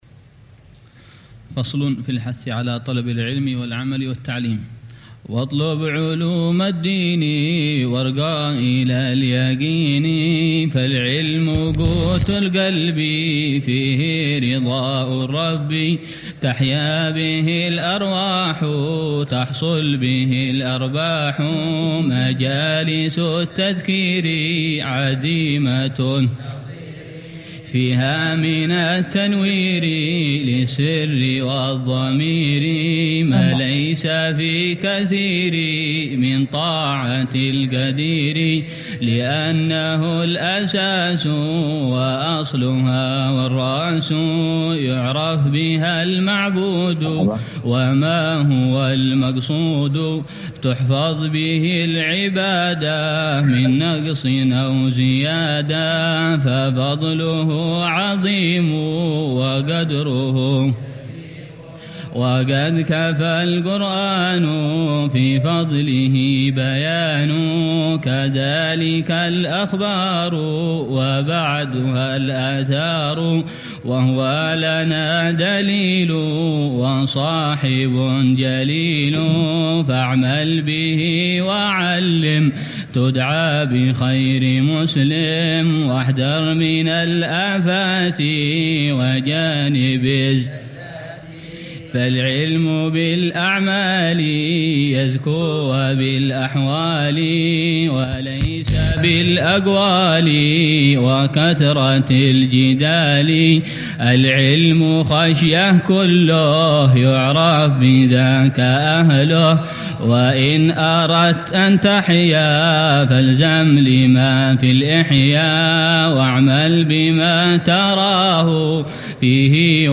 شرح الحبيب عمر بن حفيظ على منظومة «هدية الصديق للأخ والرفيق» للحبيب عبد الله بن حسين بن طاهر. الدرس الثالث عشر (25 محرم 1447هـ)